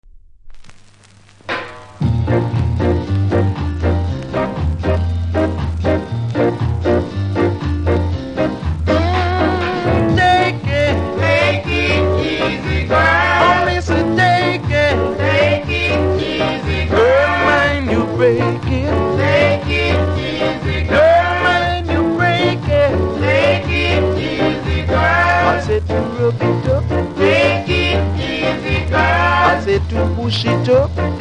多少うすキズありますが音は良好なので試聴で確認下さい。
無録音部分に少しプレス起因のノイズ感じます。